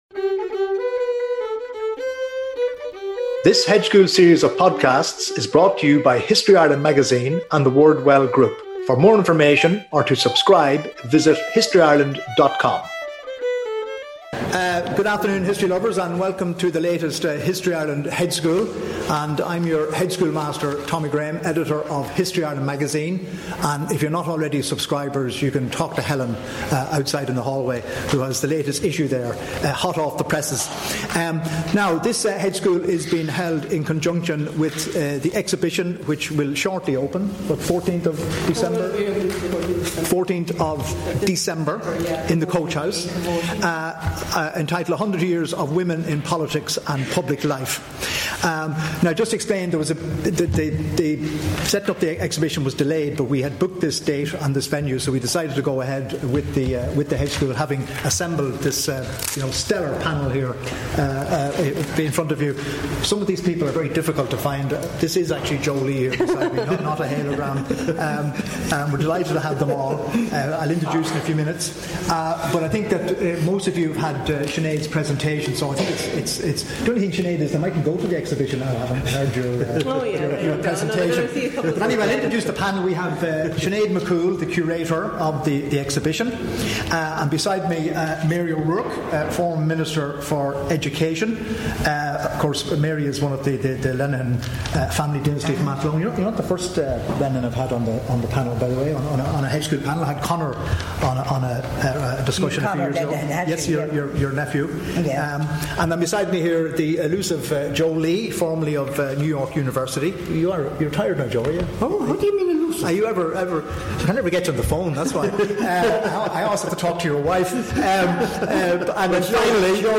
recorded @ Bedford Hall, Dublin Castle 2.30pm Friday 2 November 2018 A hundred years ago women in Ireland (then part of the UK) got the vote.